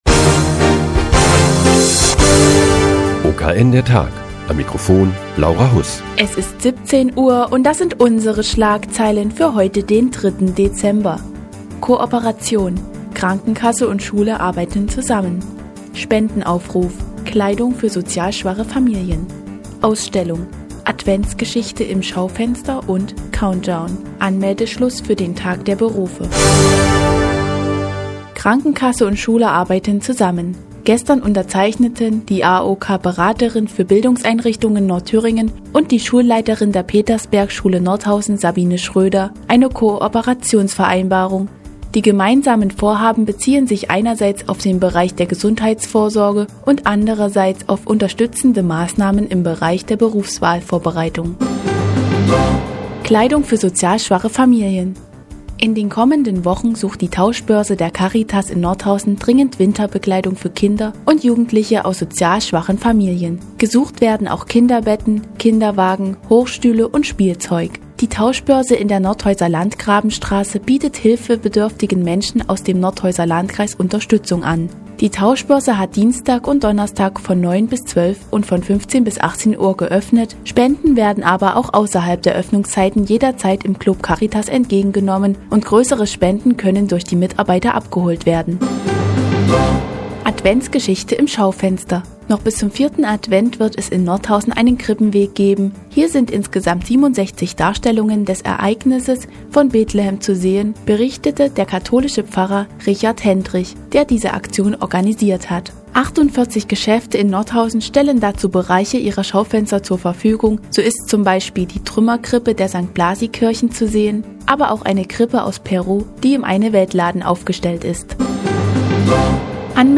Die tägliche Nachrichtensendung des OKN ist nun auch in der nnz zu hören. Heute geht es um eine Tauschbörse der Caritas und den Nordhäuser Krippenweg.